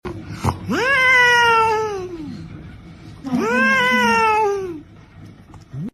cat voice are angry sound#shorts sound effects free download